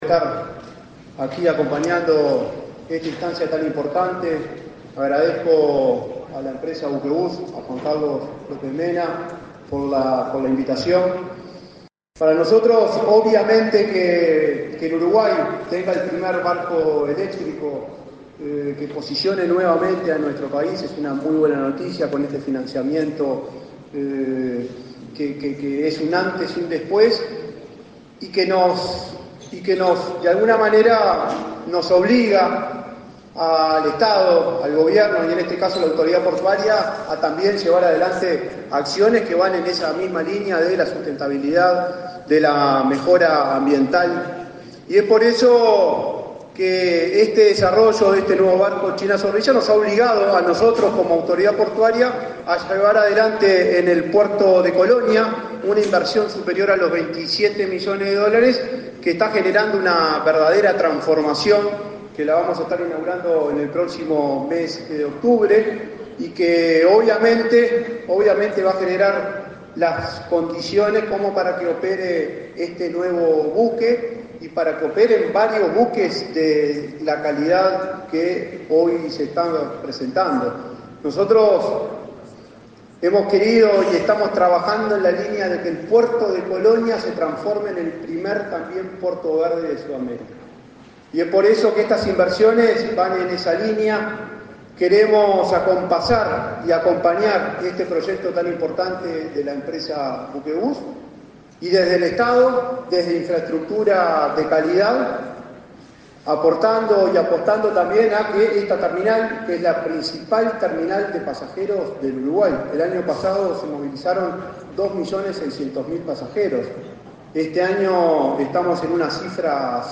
Acto por la presentanción del primer ferry eléctrico
Acto por la presentanción del primer ferry eléctrico 26/07/2024 Compartir Facebook X Copiar enlace WhatsApp LinkedIn La empresa Buquebús presentó, este 25 de julio, el primer ferry eléctrico. El buque, que se denominó China Zorrilla, conectará Colonia del Sacramento con Buenos Aires. Participaron del evento el ministro de Transporte y Obras Públicas, José Luis Falero, y el presidente de la Administración Nacional de Puertos, Juan Curbelo.